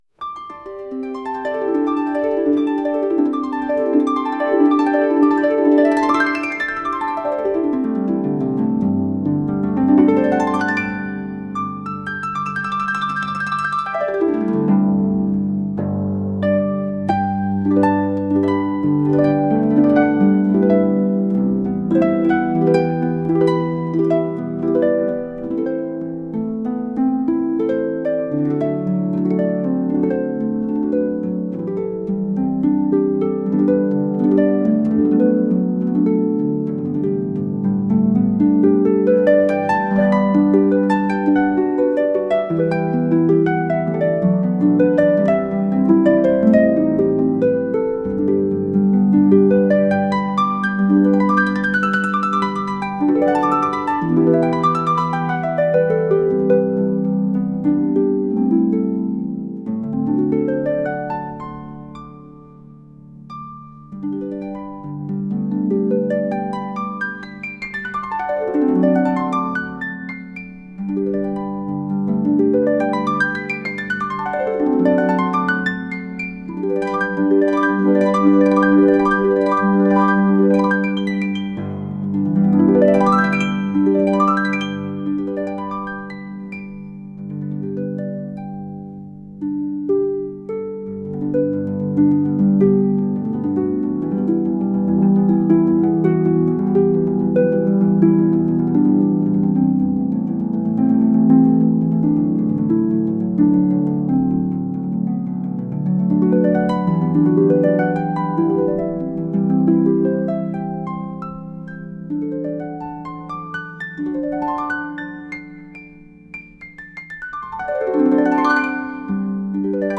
ハープのソロ曲です。